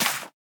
Minecraft Version Minecraft Version snapshot Latest Release | Latest Snapshot snapshot / assets / minecraft / sounds / block / sweet_berry_bush / place2.ogg Compare With Compare With Latest Release | Latest Snapshot